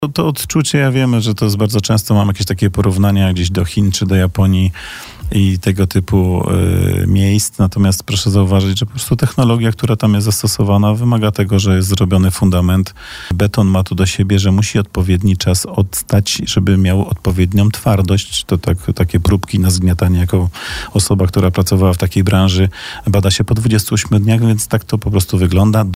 Podjęliśmy ten wątek w rozmowie z wiceprezydentem miasta.